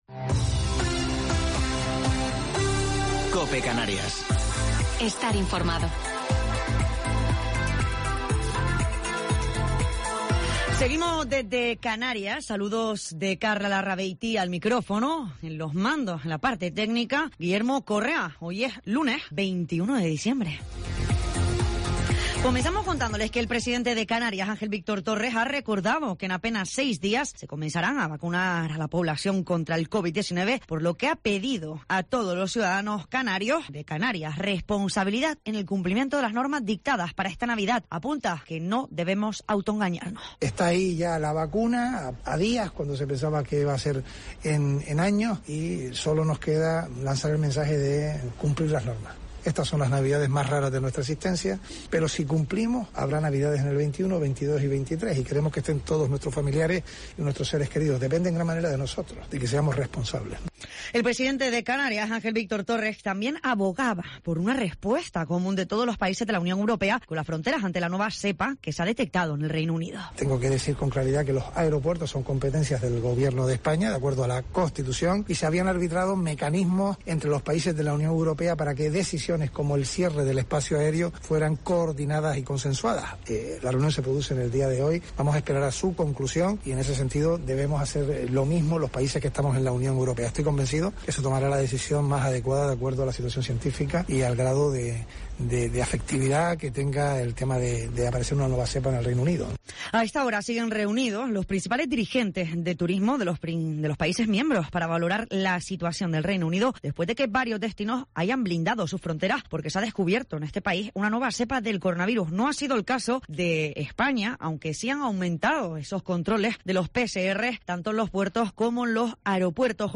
Informativo local 21 de Diciembre del 2020